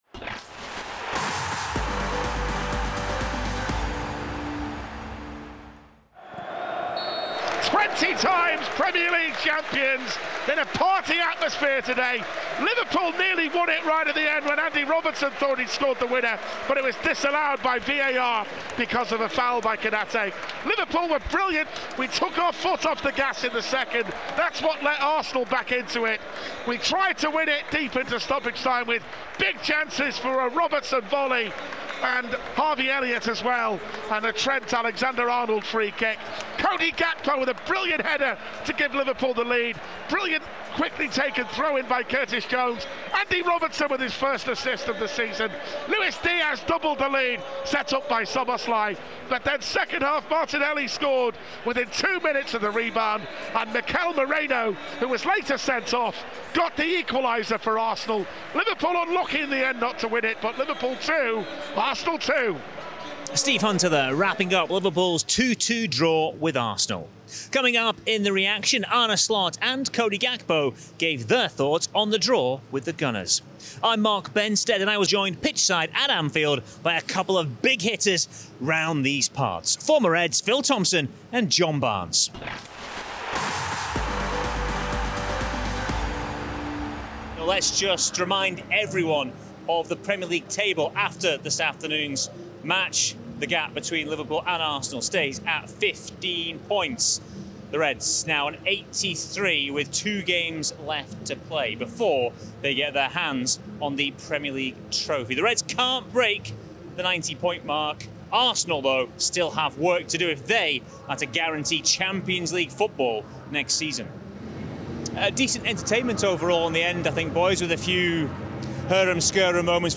Arne Slot and Cody Gakpo give their verdict on Liverpool’s 2-2 draw with Arsenal at Anfield.